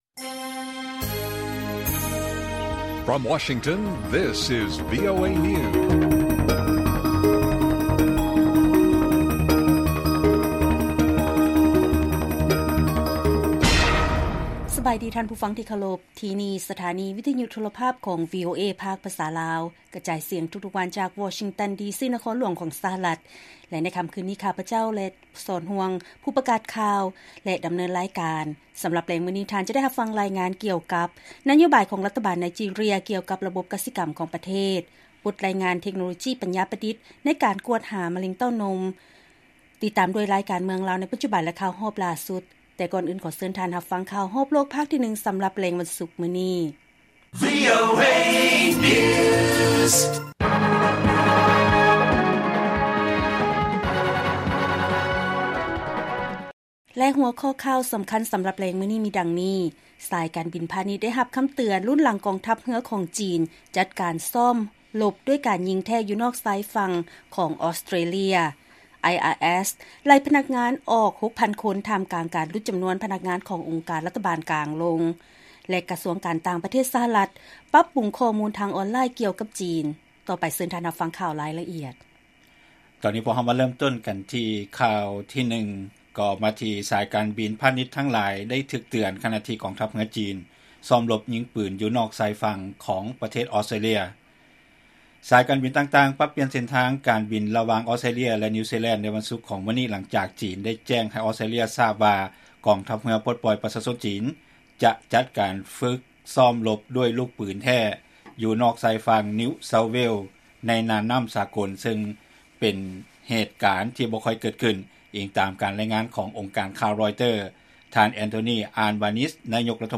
ລາຍການກະຈາຍສຽງຂອງວີໂອເອ ລາວ: ສາຍການບິນພານິດທັງຫຼາຍໄດ້ຖືກເຕືອນ ຂະນະທີ່ກອງທັບເຮືອຈີນ ຊ້ອມລົບຍິງປືນຢູ່ນອກຊາຍຝັ່ງອອສເຕຣເລຍ